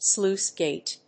アクセントslúice gàte [vàlve]